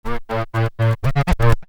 Scratch 110.wav